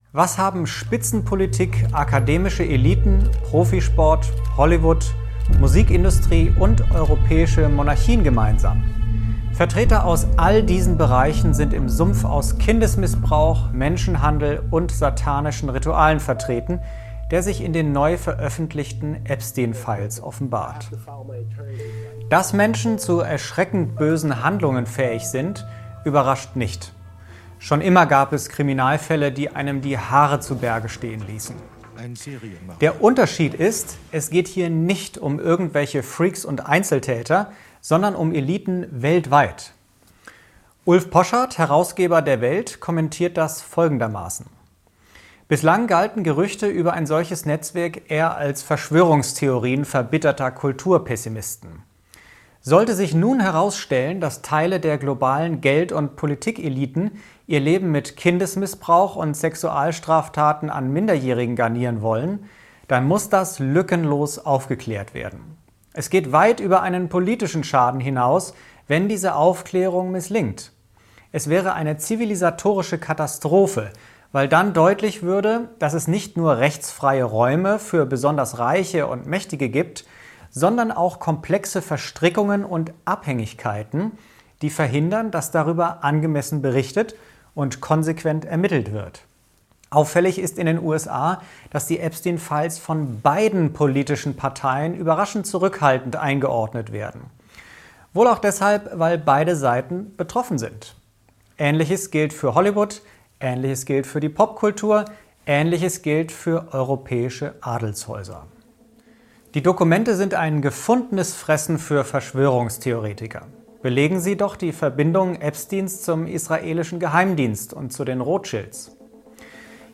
Kategorie News